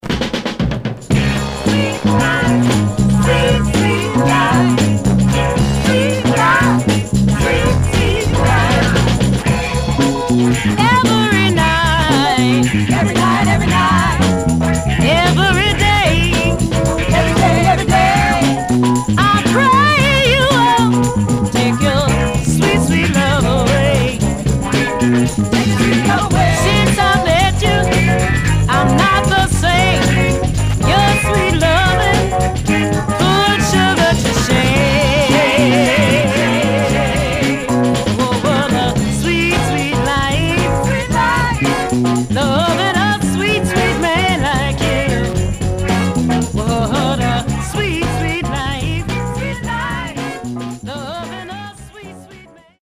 Stereo/mono Mono
Folk